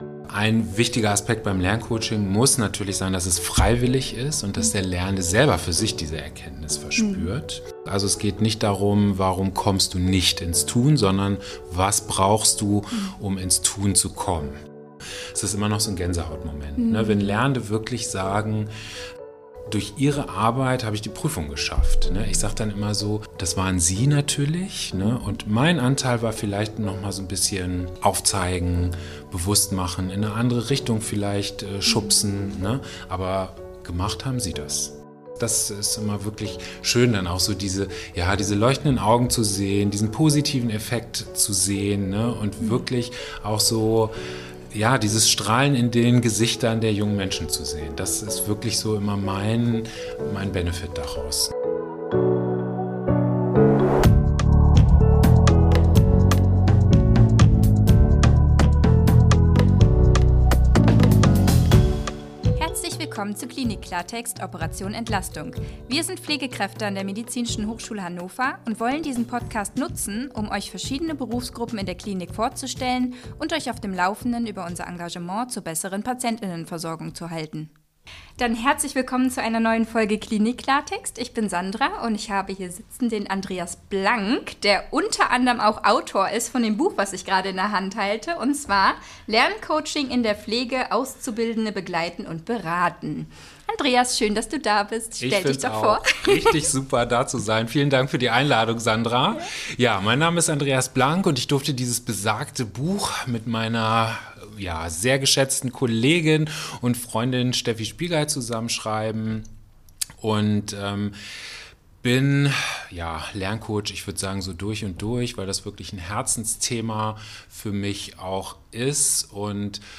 Lerncoach – Ein Interview